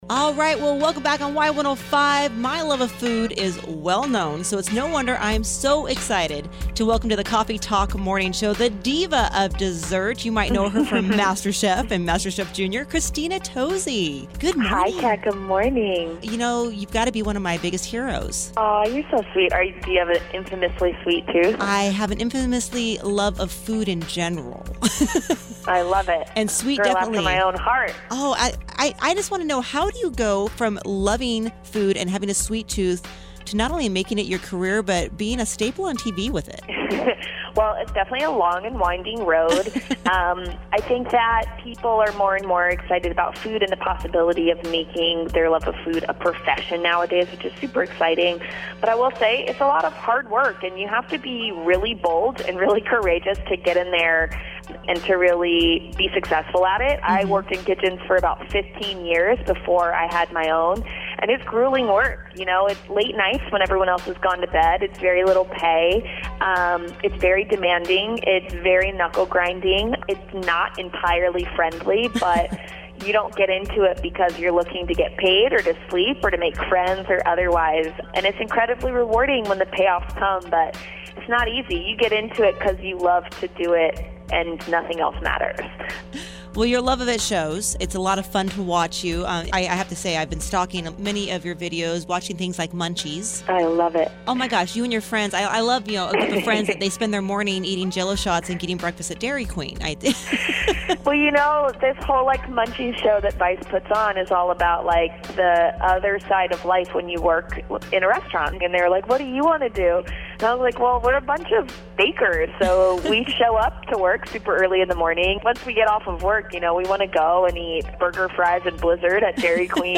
Christina Tosi Interview